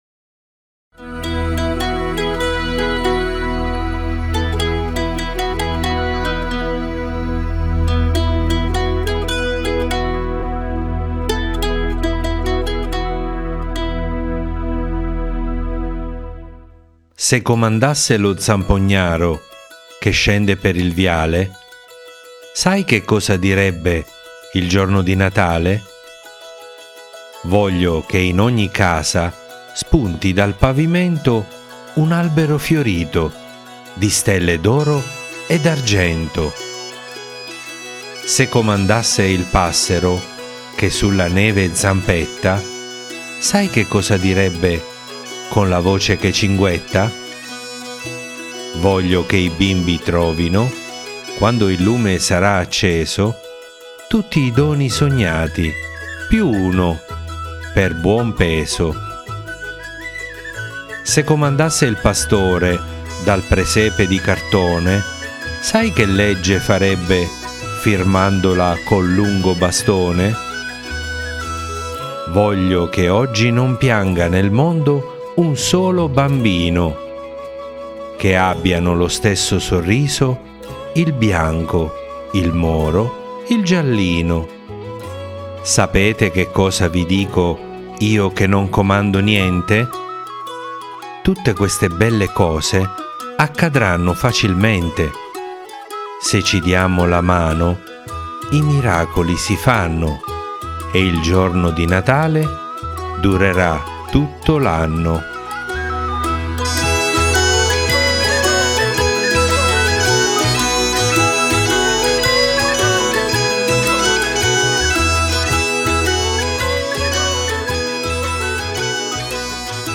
Leggi e ascolta la poesia “lo zampognaro” di Gianni Rodari